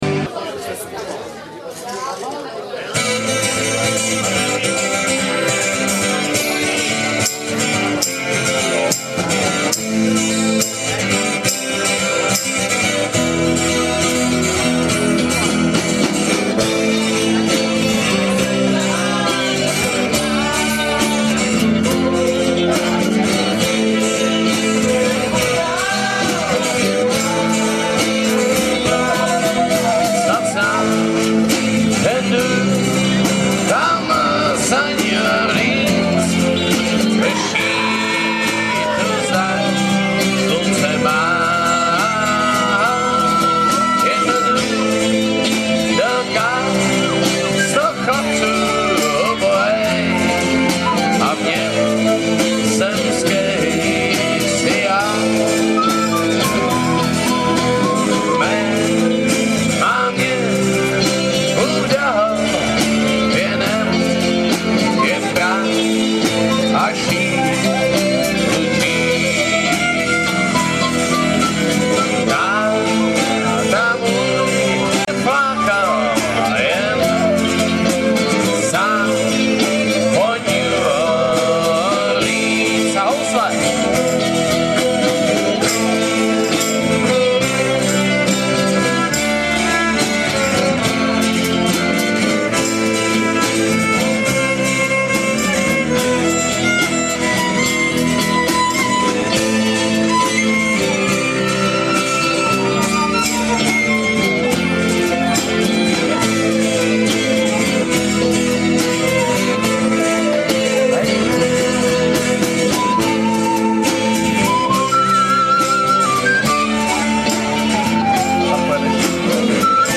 český renesanční country folk rock